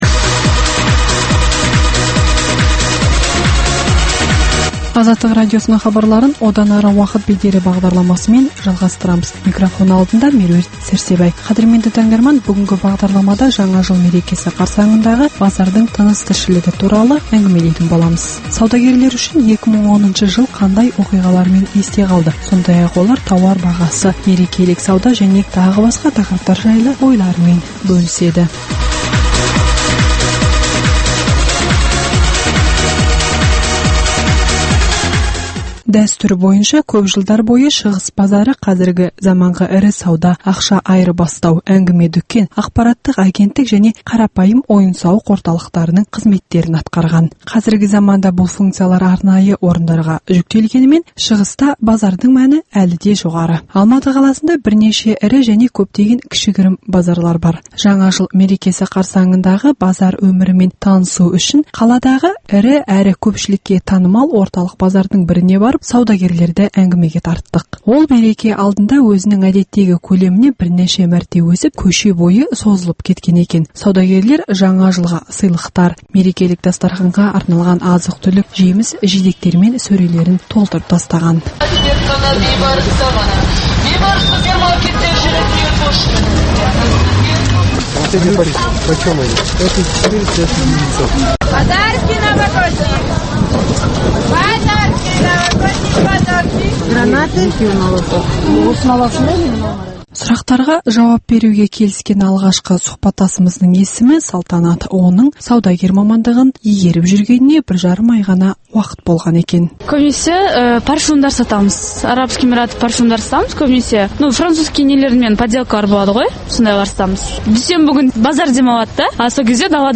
Жаңа жыл мерекесі қарсаңындағы базар өмірімен танысу үшін қаладағы ірі әрі көпшілікке танымал орталық базардың біріне барып, саудагерлерді әңгімеге тарттық.